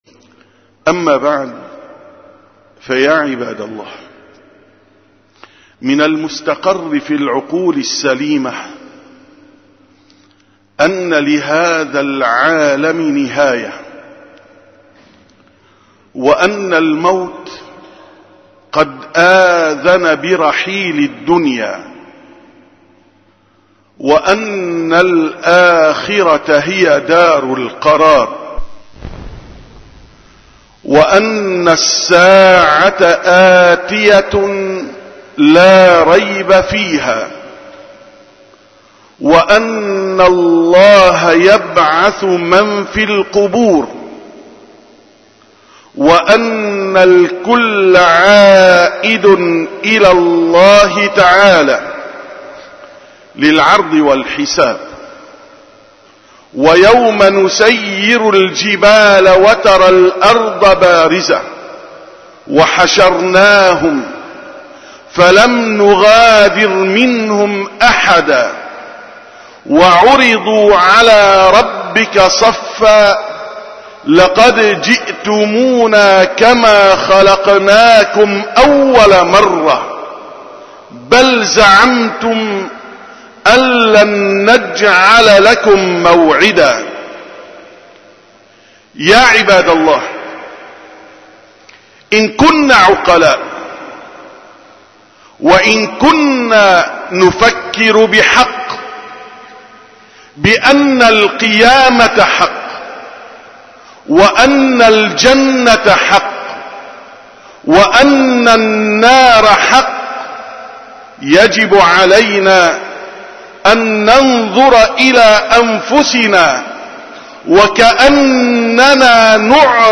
خطبة الجمعة: ﴿فَوَرَبِّكَ لَنَسْأَلَنَّهُمْ أَجْمَعِينَ﴾